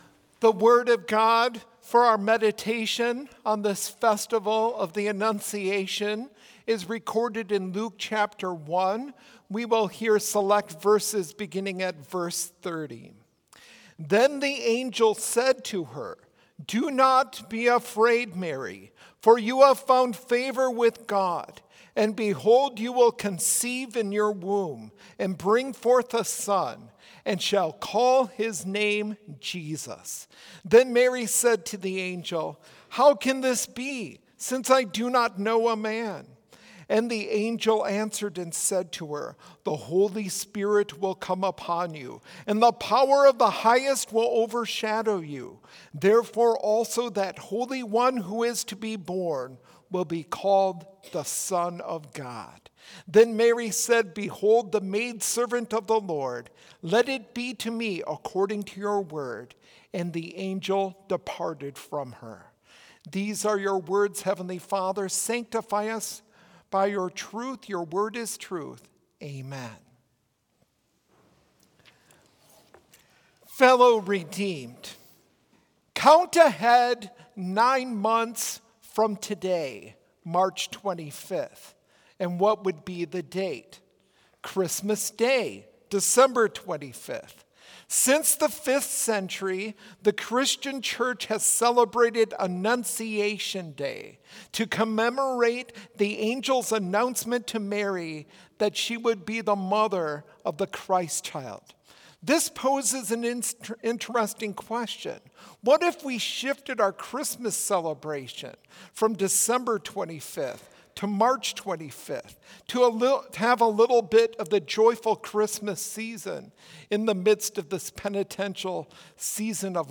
Complete service audio for Chapel - Tuesday, March 25, 2025